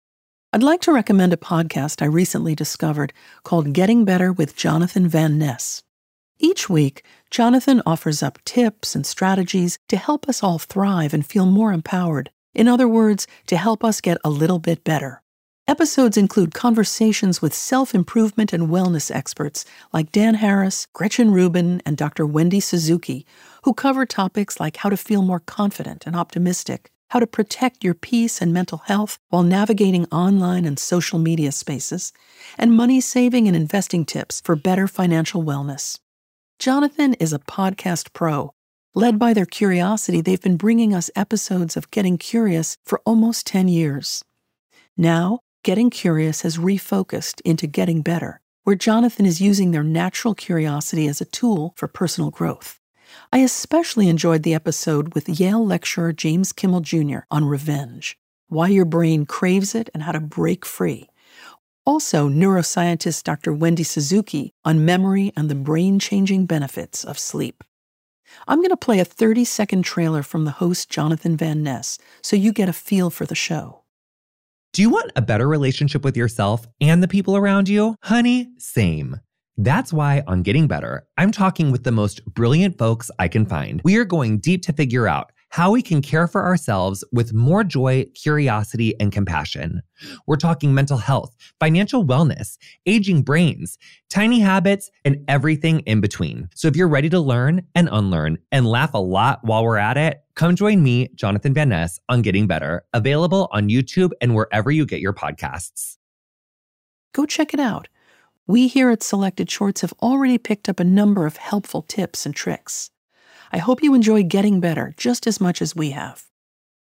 Host Meg Wolitzer presents Getting Better with Jonathan Van Ness. Each week, Jonathan offers tips and strategies to help us thrive while hosting incredible guests like Dan Harris, Gretchen Rubin and Wendy Suzuki.